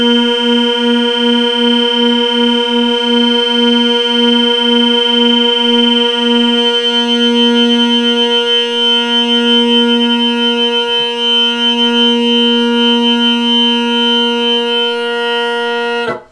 This bum reed needed a replacement because it sounds terrible. It produces this huge, nasty overtone that just hurts to listen to. Which makes it a great reed to prove my point, you can definitely hear the fundamental beating frequency along with an overtone beating frequency.
Notice how much smaller the fundamental beating frequency is relative to the high pitched, grating overtone beating frequency. Even near the end of the audio file when the drones are pretty close to in tune, you hear the overtone beating frequency go in and out as I try to find the perfect tuning spot for the second drone.
Tenor drone tuning
drone_tuning.wav